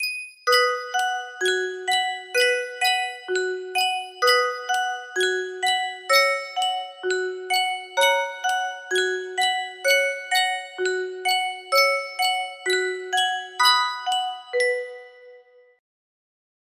Sankyo Music Box - Tom Dooley UFH music box melody
Full range 60